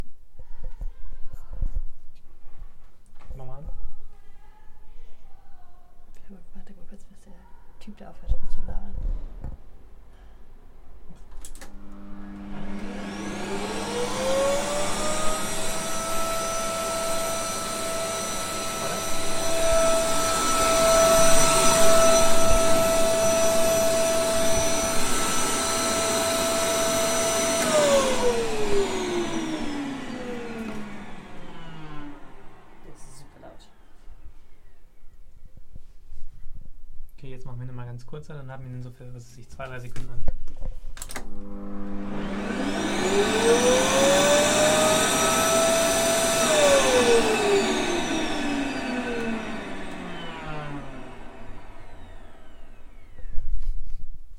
描述：简单地记录我在阳台上使用的胡佛。
Tag: 灰尘 真空 垃圾 清洗 胡佛 清洁